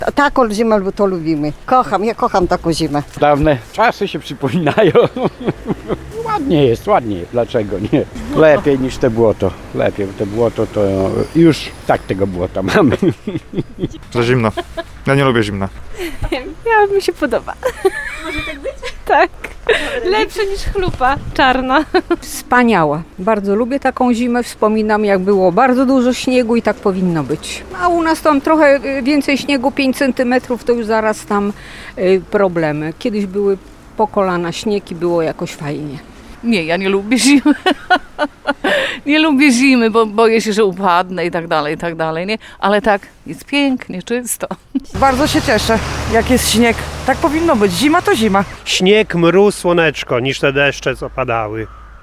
Jak powiedzieli reporterce Radia 5 ełczanie, przypominają się stare dobre czasy.